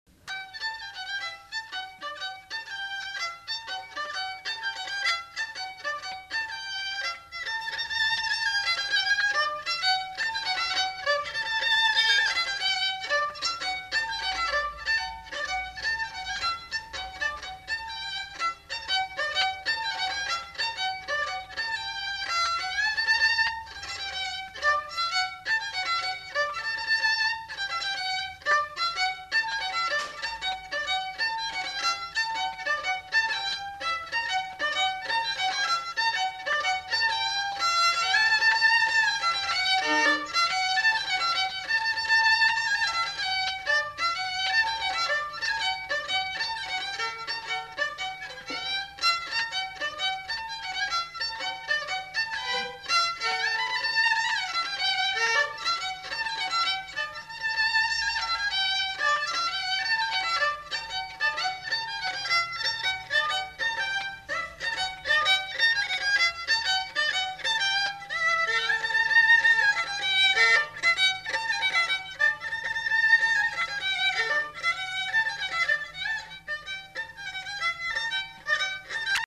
Aire culturelle : Lugues
Lieu : Casteljaloux
Genre : morceau instrumental
Instrument de musique : violon
Danse : rondeau